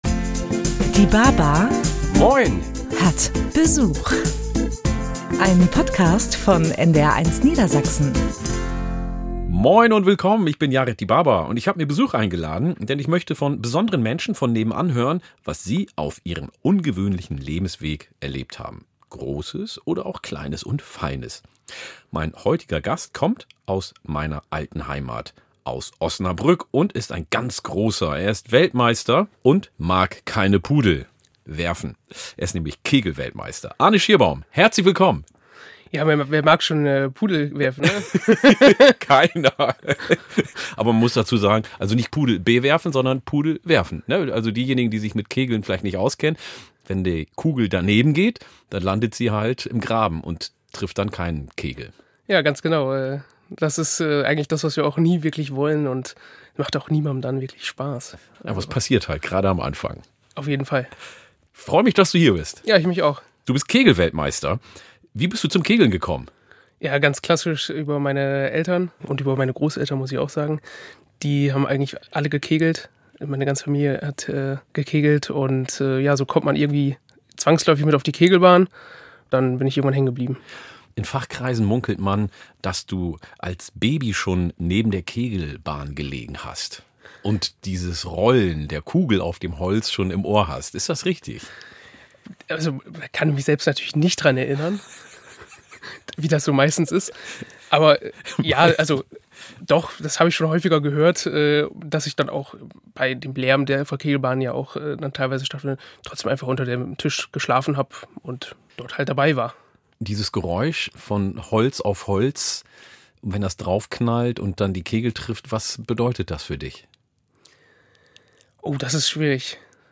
Yared Dibaba schnackt gerne!
Jeden ersten Samstag im Monat lädt er spannende Menschen von nebenan ein und plaudert mit ihnen über Gott und die Welt – und über das, was sie ausmacht.